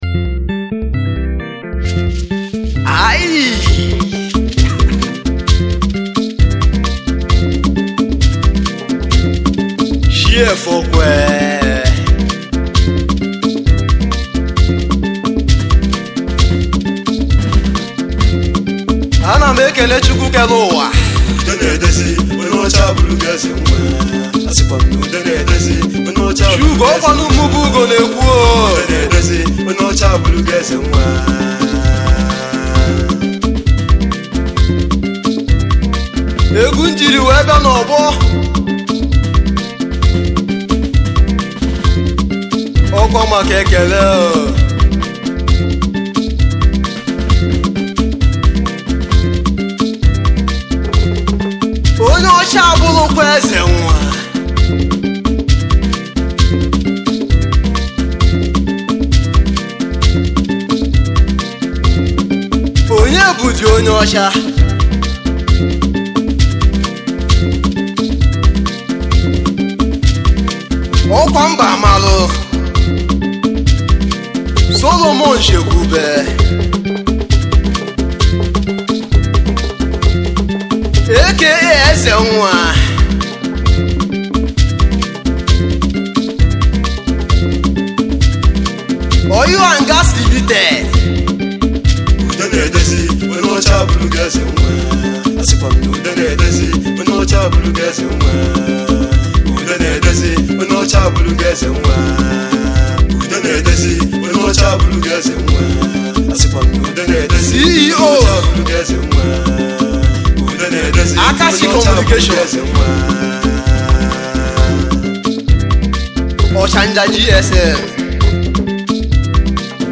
igbo highlife
highlife music band